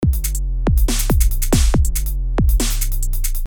沉重的Dubstep Glitch节拍
描述：沉重的配音节拍与低音下降
Tag: 140 bpm Dubstep Loops Drum Loops 590.67 KB wav Key : Unknown